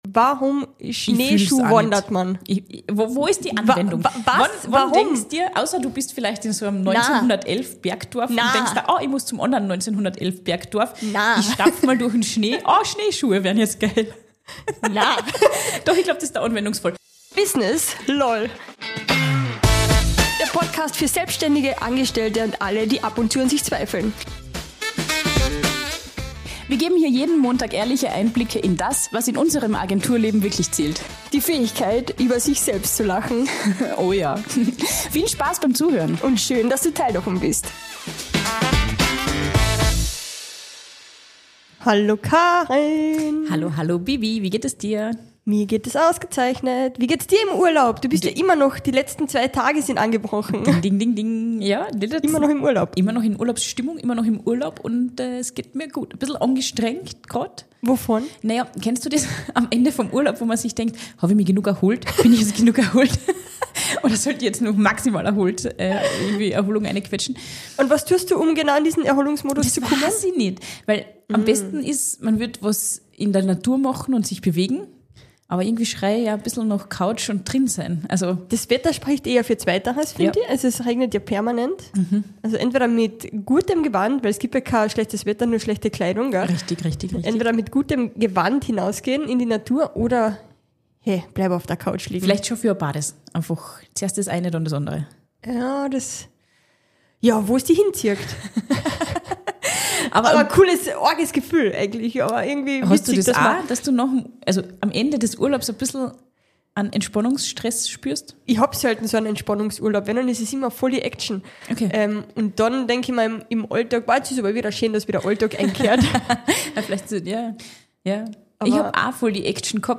Genau darüber haben die beiden sich in der aktuellen Folge unterhalten, Sport-Fail natürlich inklusive.